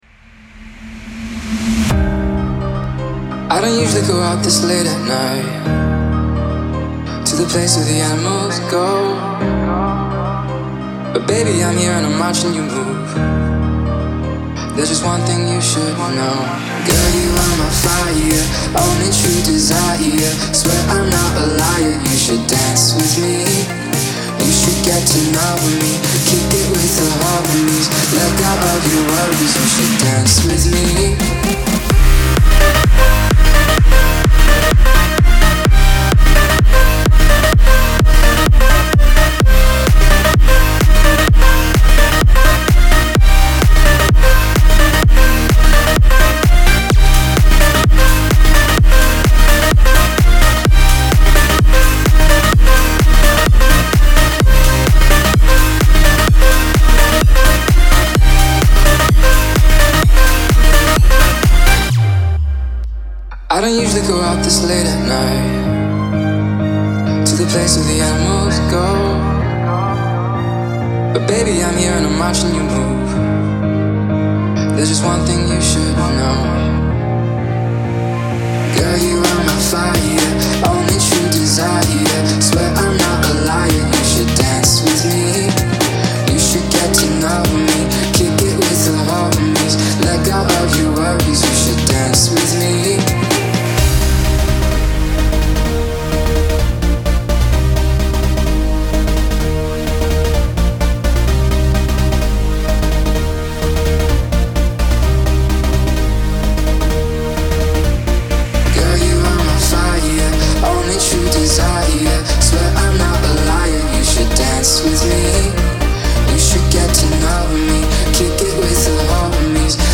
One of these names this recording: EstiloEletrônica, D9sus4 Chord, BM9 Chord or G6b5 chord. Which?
EstiloEletrônica